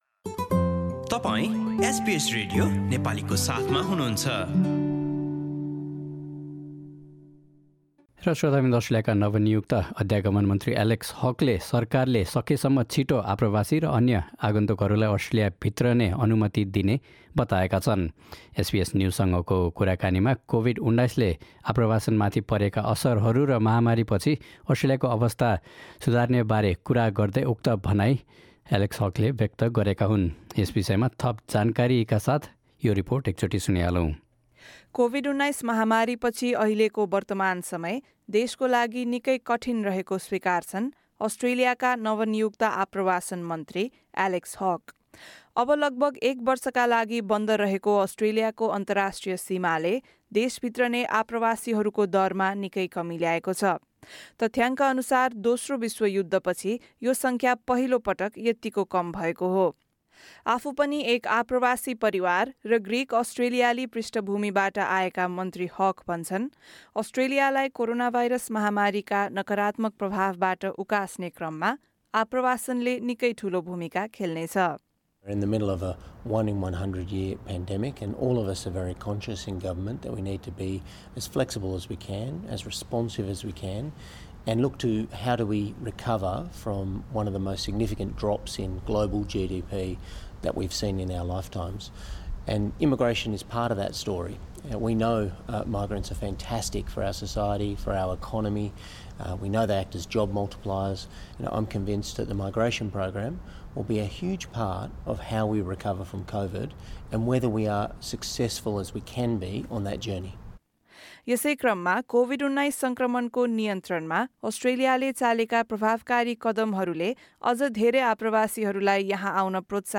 एसबीएस न्युजसँगको एक विशेष साक्षात्कारमा बोल्दै, अस्ट्रेलियाका सङ्घीय अध्यागमन मन्त्री एलेक्स हकले आफ्नो सरकार आप्रवासी तथा भिजिटर भिसा हुने मानिसहरूलाई छिटोभन्दा छिटो देश भित्र्याउने योजनामा रहेको बताएका छन्।